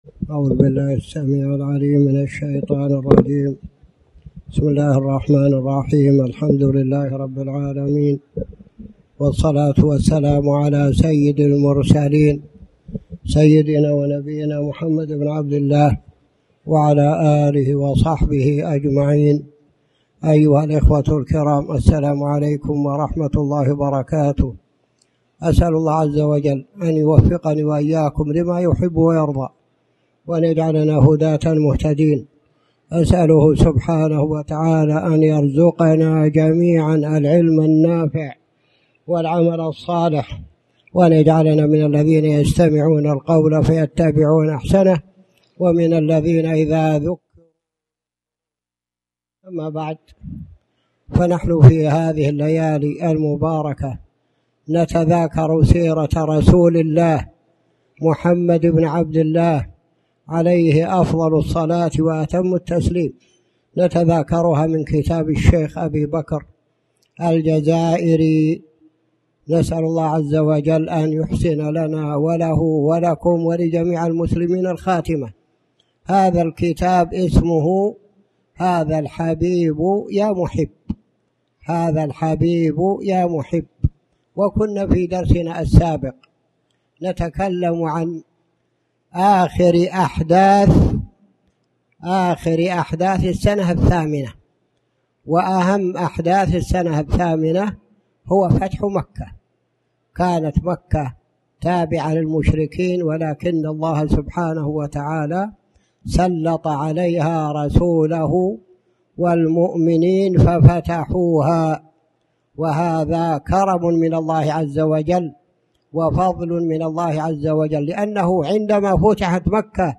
تاريخ النشر ٢٥ محرم ١٤٣٩ هـ المكان: المسجد الحرام الشيخ